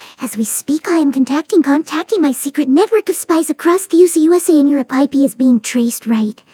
这个工具来自MIT，并且还是在线、免费，可以用它来生成各种字符的44.1 kHz声音。
这些声音是使用多种音频合成算法定制的深层神经网络实时生成的。
接下来是《My Little Pony》中Fluttershy的声音。